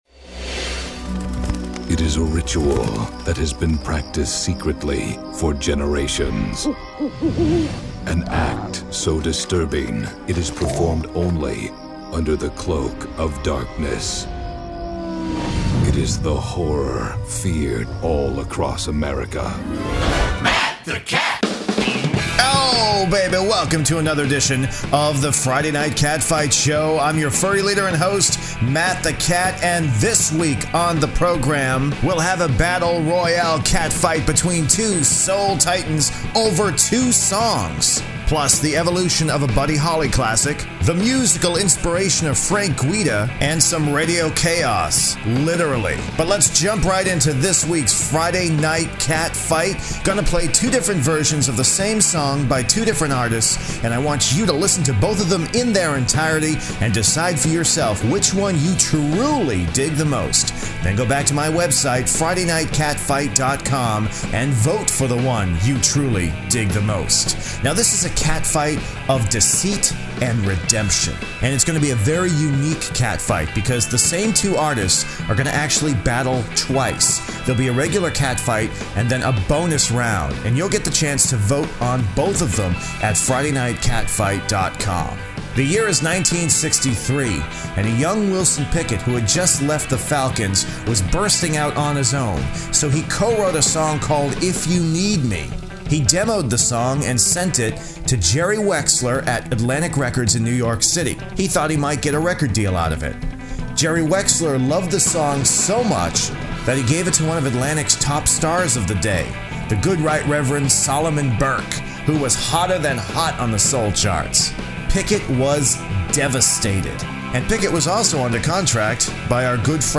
Our friend, the legendary Lloyd Price stops in with some background information about this soul rumble.